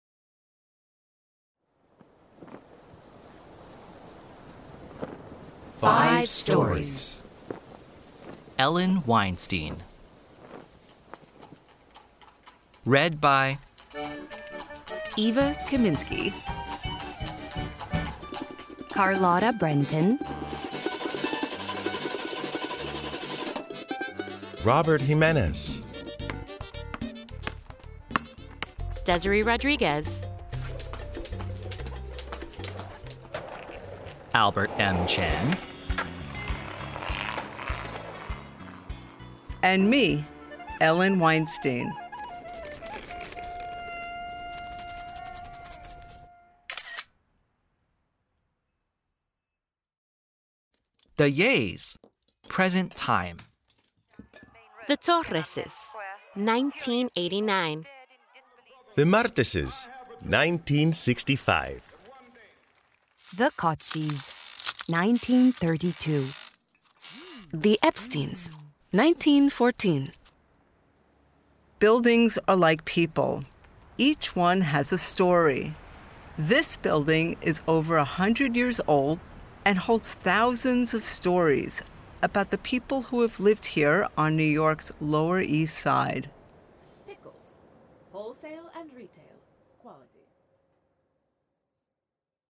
Narrator: